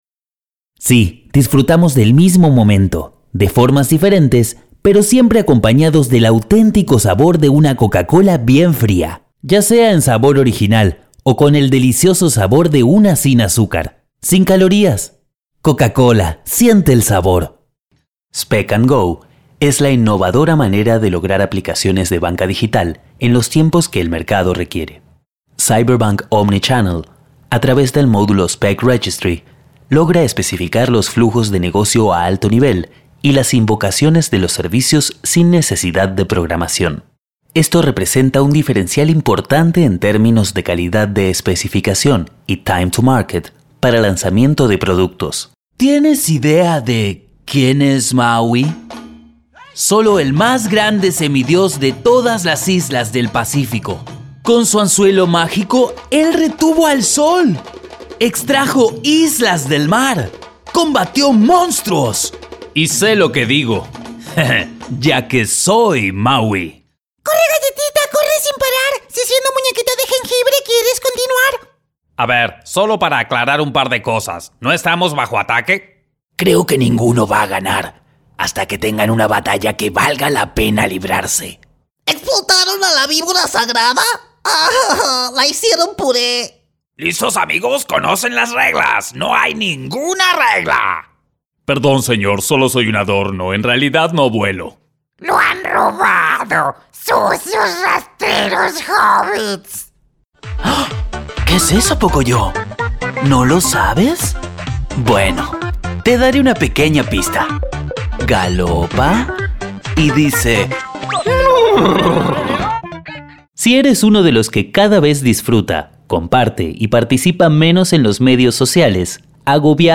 Rápido y profesional con estudio propio de alta calidad. Vos brillante, agradable y versátil.
Sprechprobe: Sonstiges (Muttersprache):
Bright, powerful and versatile voice.